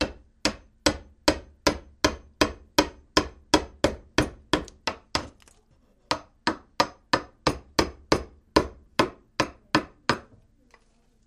Chisel and Hammer, Loop